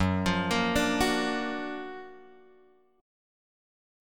F# Augmented Major 7th